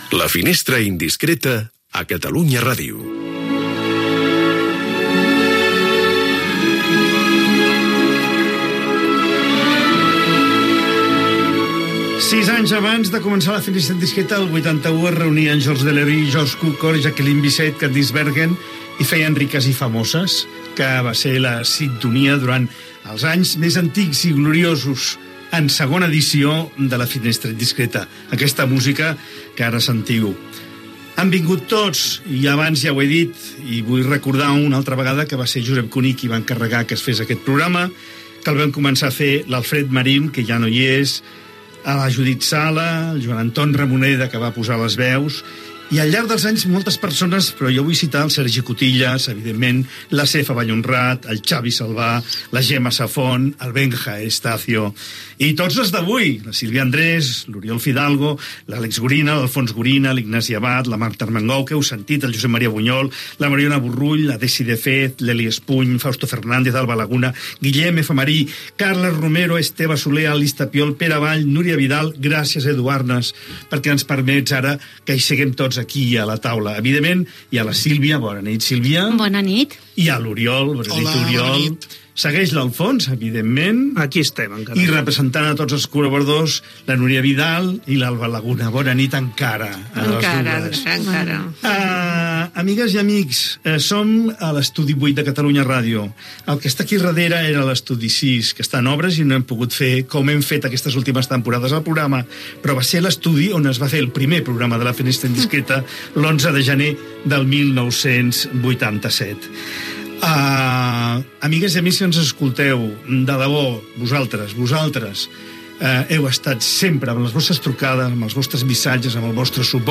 Indicatiu del programa i final de la segona hora: record dels inicis del programa, l'equip actual, agraïment a l'audiència del programa, comiat amb el fragment d'una pel·lícula i un tema musical.